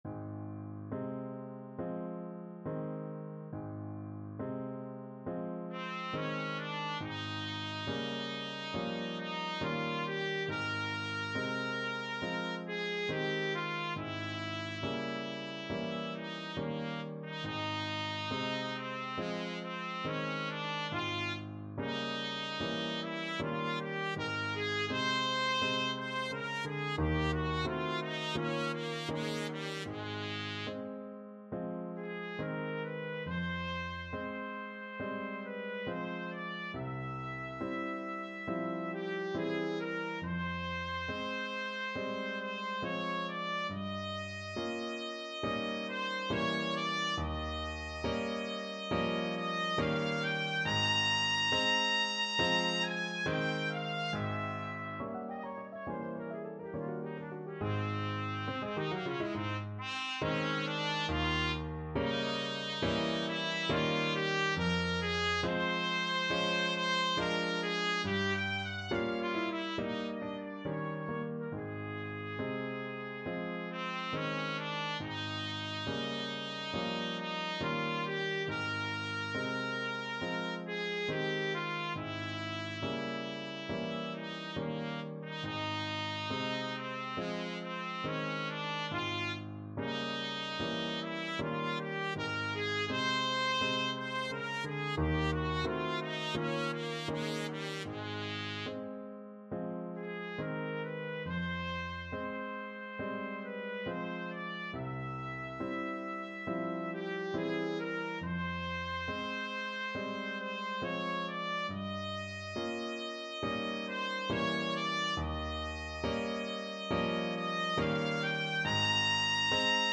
Classical Clara Schumann Piano Concerto in Am (Op.7) Second Movement Main Theme Trumpet version
Play (or use space bar on your keyboard) Pause Music Playalong - Piano Accompaniment Playalong Band Accompaniment not yet available reset tempo print settings full screen
Trumpet
Ab major (Sounding Pitch) Bb major (Trumpet in Bb) (View more Ab major Music for Trumpet )
4/4 (View more 4/4 Music)
Andante non troppo con grazia =69
Classical (View more Classical Trumpet Music)